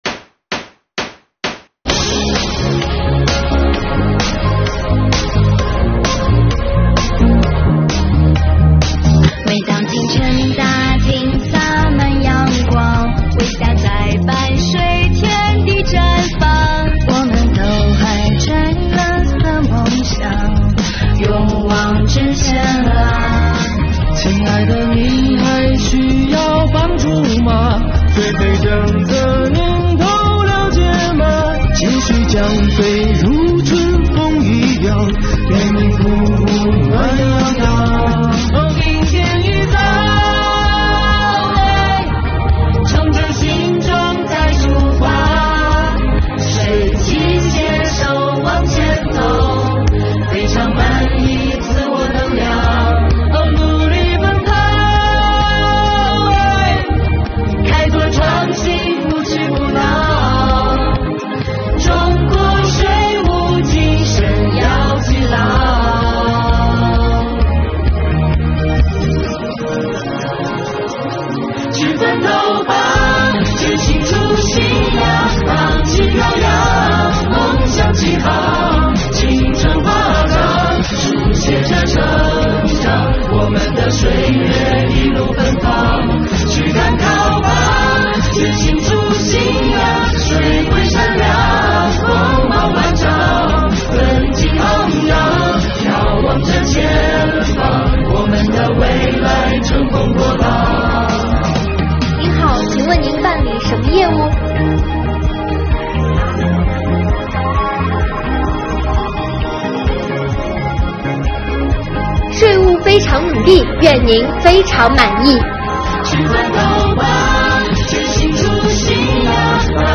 本作品拍摄、剪辑和音乐颇具青春活力，具有视觉冲击力。